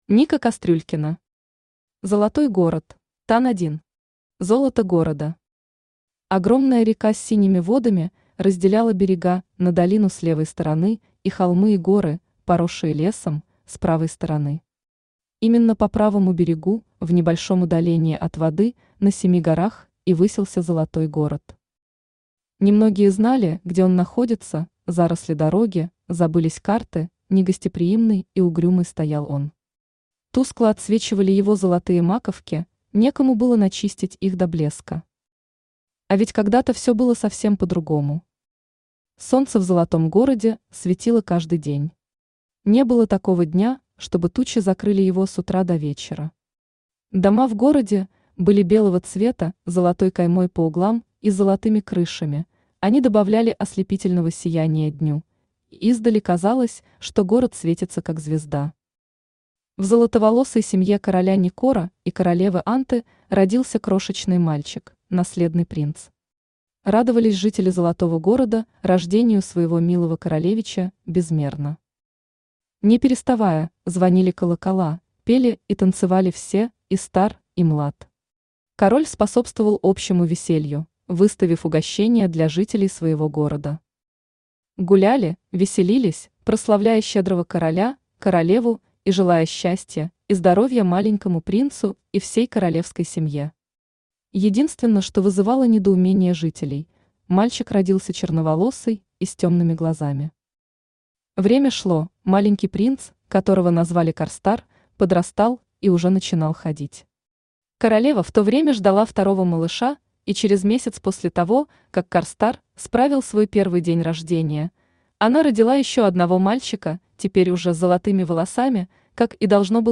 Aудиокнига Золотой город Автор Ника Кастрюлькина Читает аудиокнигу Авточтец ЛитРес.